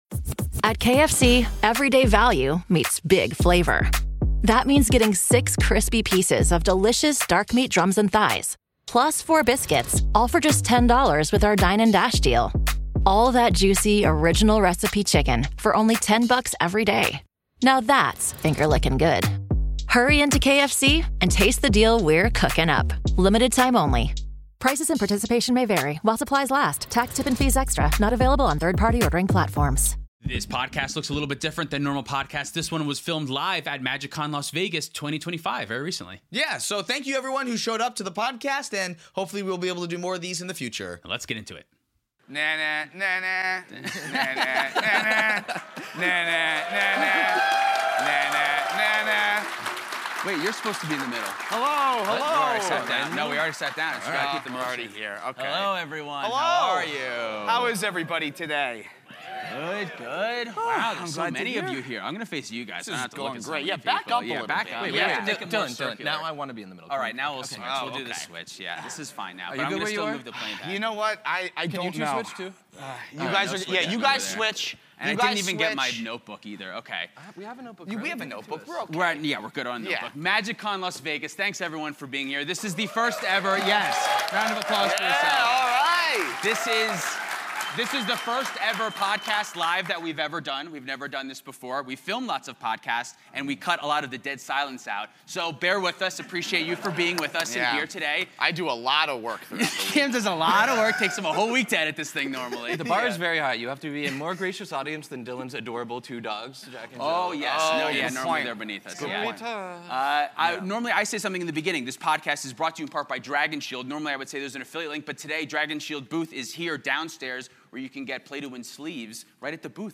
OUR LIVE PODCAST FROM MAGICCON VEGAS WHERE WE RANKED A LIST OF cEDH CARDS BUT WE DIDN'T KNOW WHAT WAS ON THE LIST